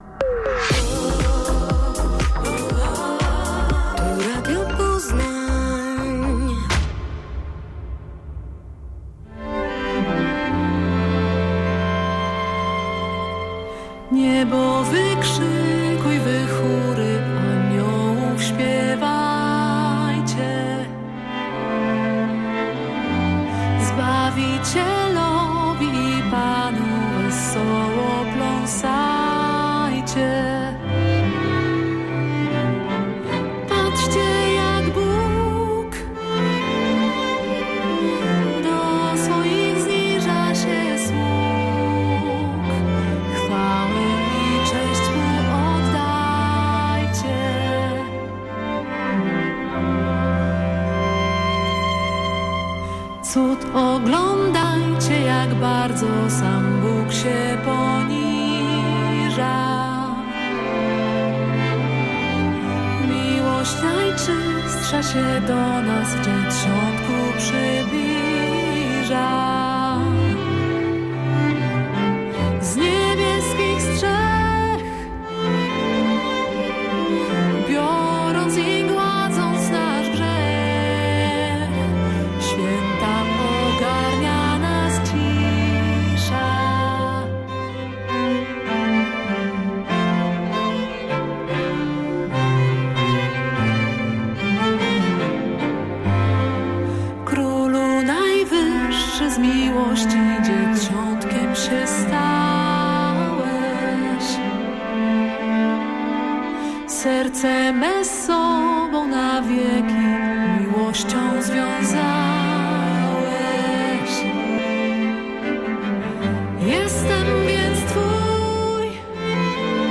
W pięknych kolędach usłyszą Państwo trochę klasyki, jazzu, elementów improwizacji, ale przede wszystkim ciepłe, naturalne i akustyczne brzmienie...